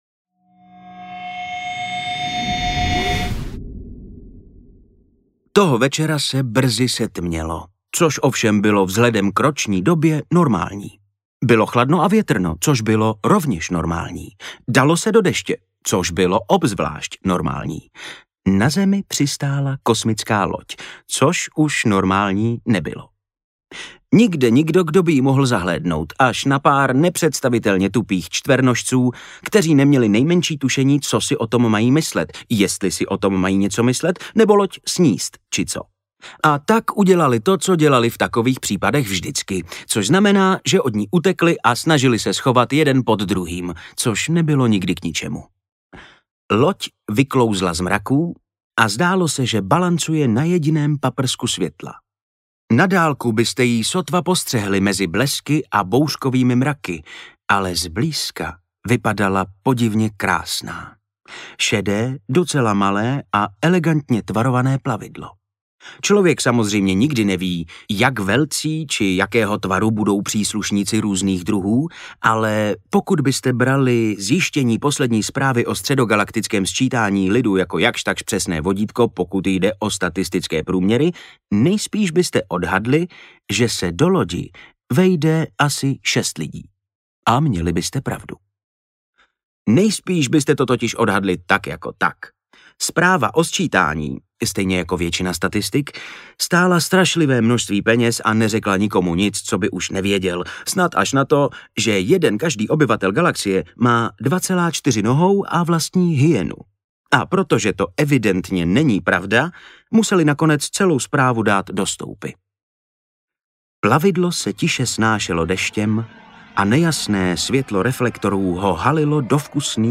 Stopařův průvodce galaxií 4: Sbohem, a díky za ryby audiokniha
Ukázka z knihy
• InterpretVojtěch Kotek